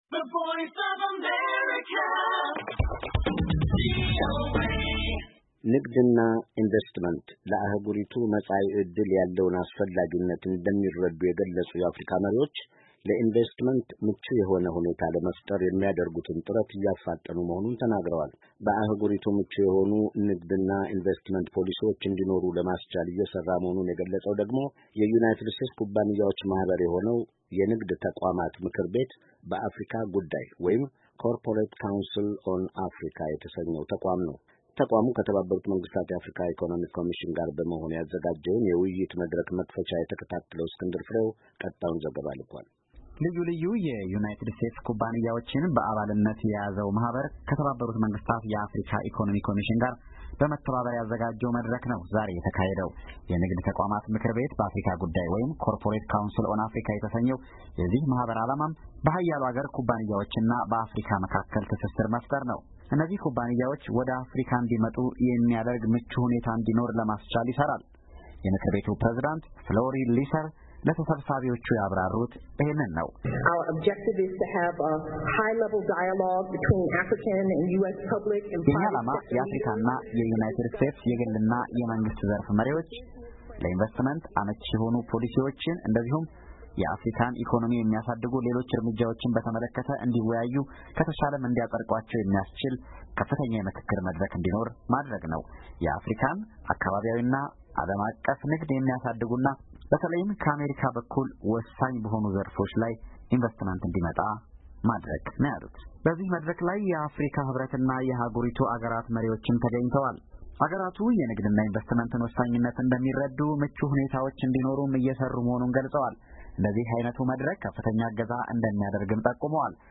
ተቋሙ ከተባበሩት መንግሥታት የአፍሪካ ኢኮኖሚክ ኮሚሽን ጋር በመሆን ያዘጋጀውን የውይይት መድረክ መክፈቻ ዘገባ።